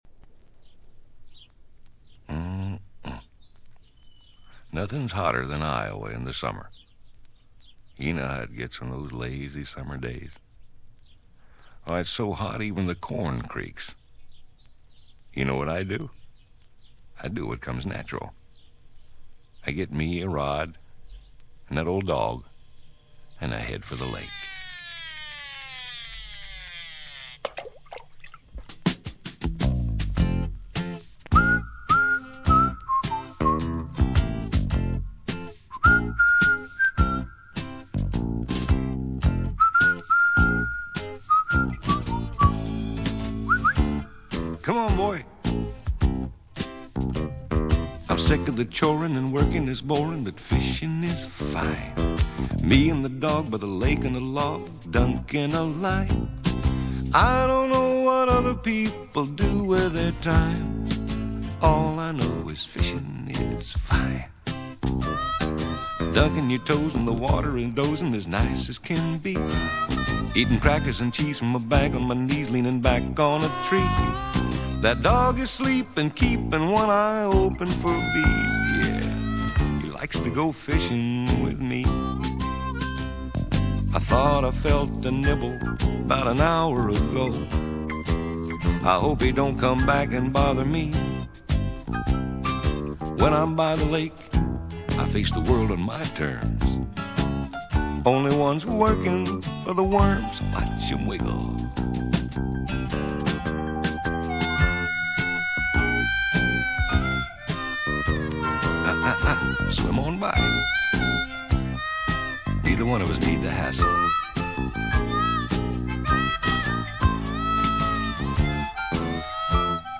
This folk music
drums
piano
bass guitar
harmonica
banjo
strings
vocals
Folk music--Iowa